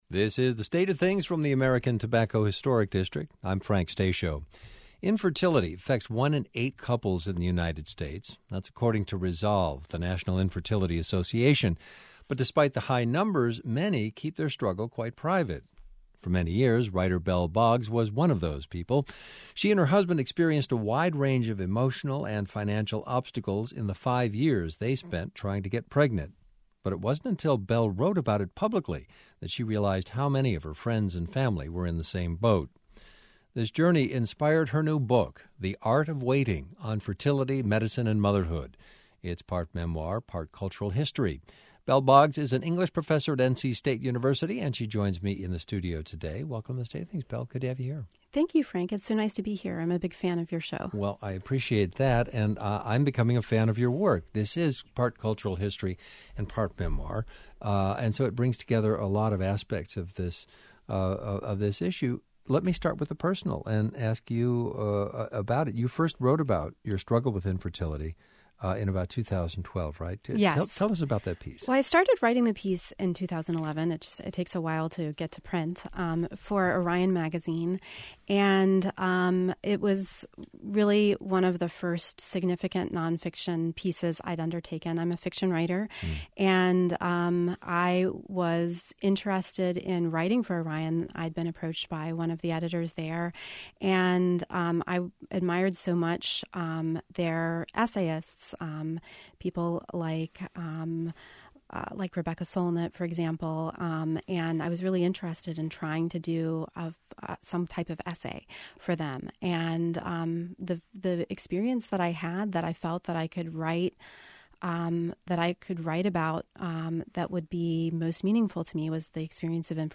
Note: This is a rebroadcast.